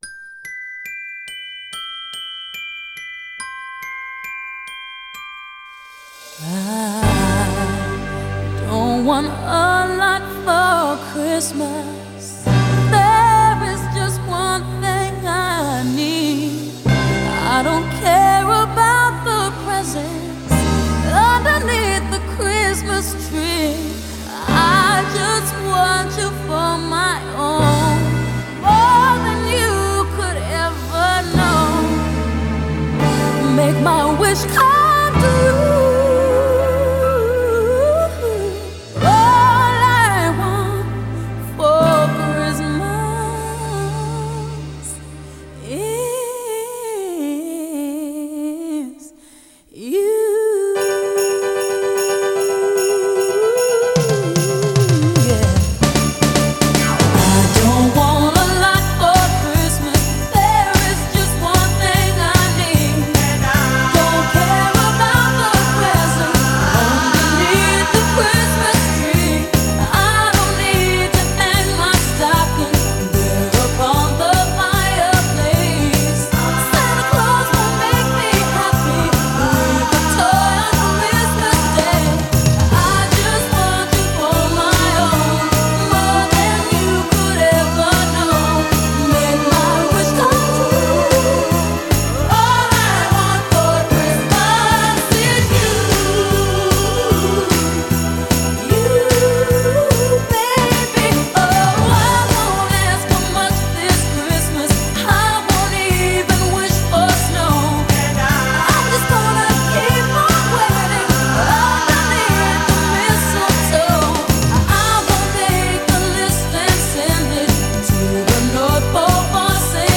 это культовая рождественская поп-песня
Смесь R&B и поп создает яркое и праздничное звучание
мощный вокал